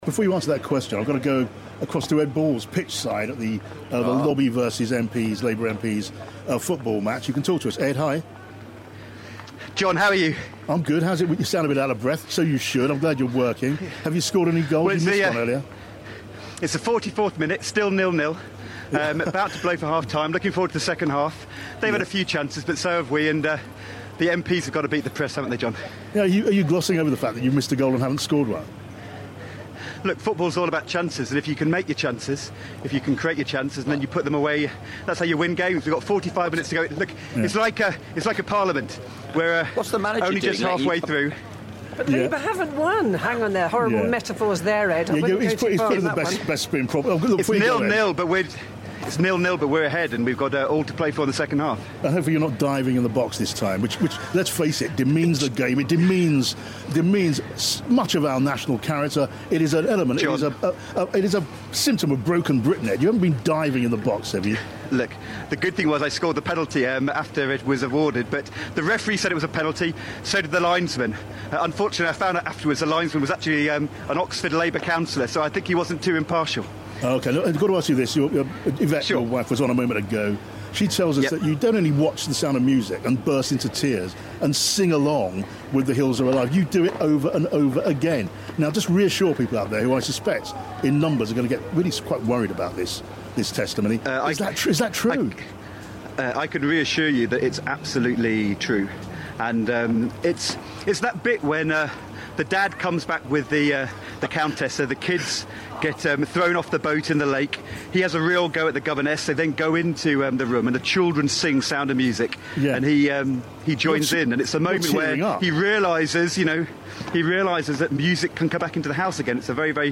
An out of breath Ed Balls...
Ed Balls talks to John Pienaar, pitch side from the MPs V Hacks football match. Does he really cry when he watches The Sound of Music?